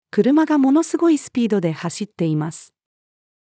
車が物凄いスピードで走っています。-female.mp3